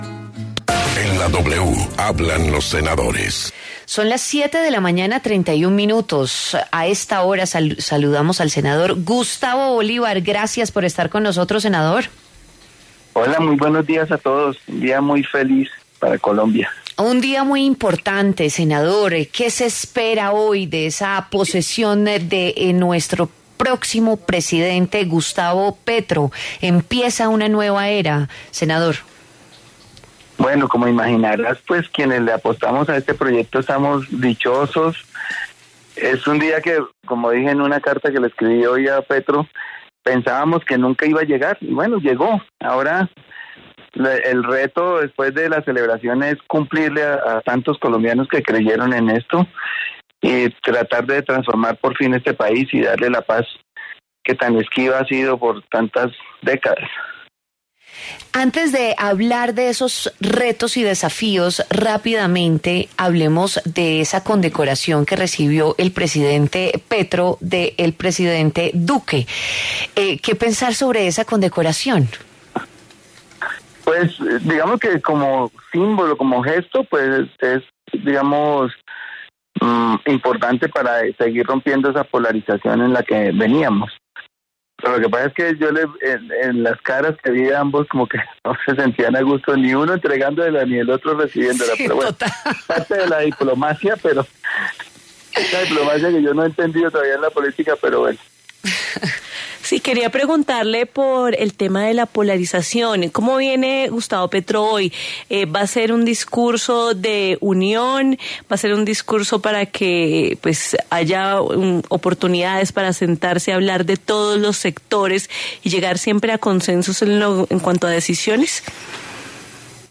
En W Fin de Semana, el senador Gustavo Bolívar conversó sobre los desafíos de Gustavo Petro tras su posesión como presidente de la República este 7 de agosto.